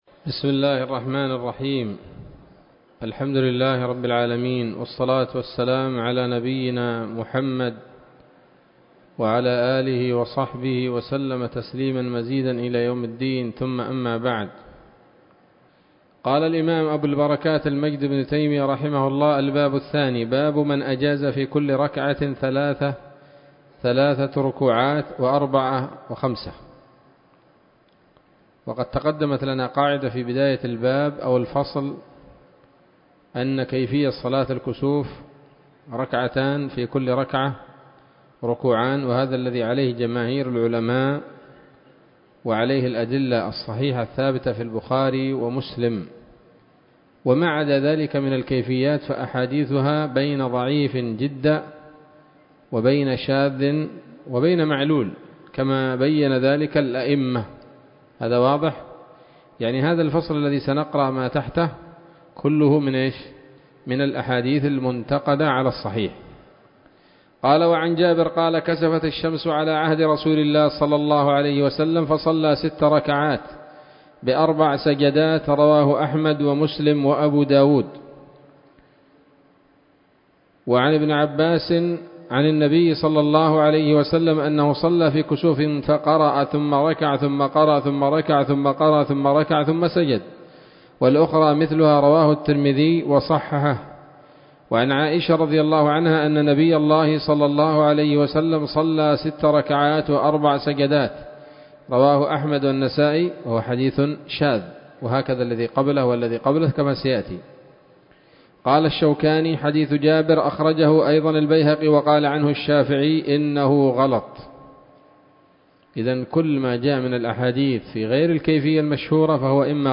الدرس الخامس من ‌‌‌‌كتاب صلاة الكسوف من نيل الأوطار